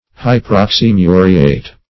Hyperoxymuriate \Hy`per*ox`y*mu"ri*ate\, n.